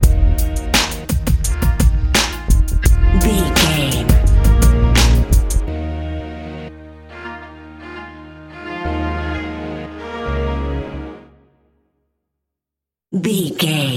Epic / Action
Aeolian/Minor
E♭
drum machine
synthesiser
funky